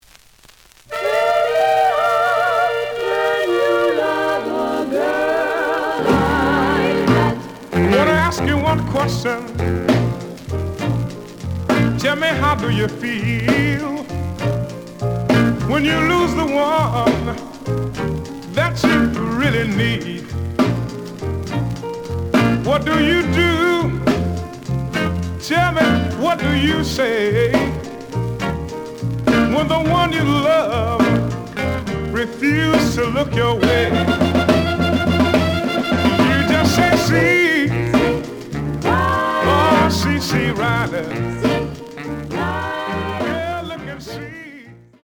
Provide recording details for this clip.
The audio sample is recorded from the actual item. Slight noise on B side.